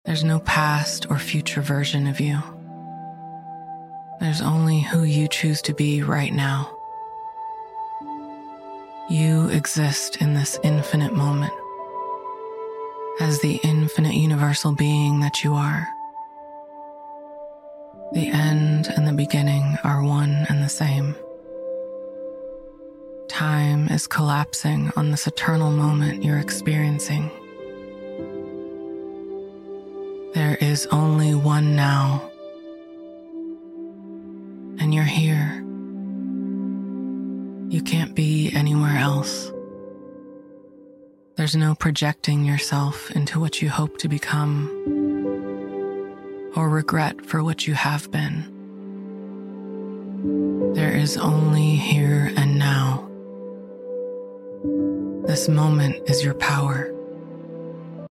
--- This is an excerpt from my latest podcast episode/meditation, out now on all platforms.